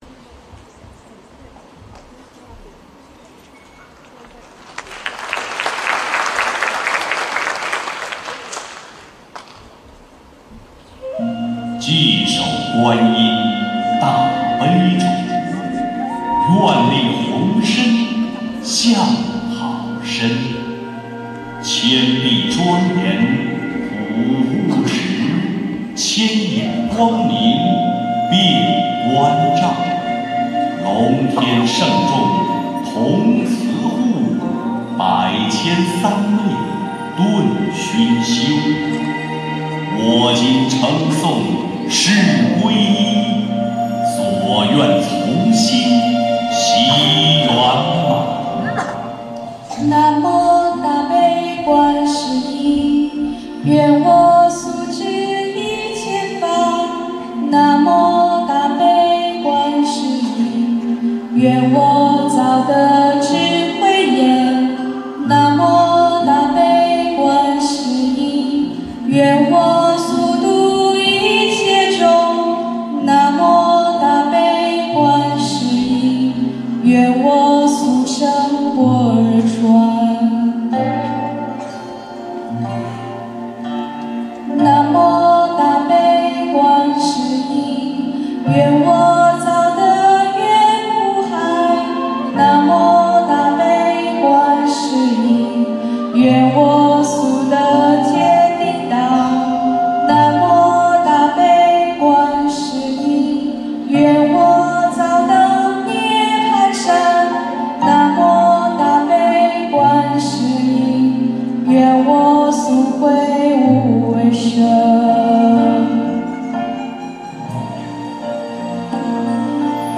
音频：新加坡佛友合唱《观音菩萨发愿偈》一曲一天堂、一素一菩提！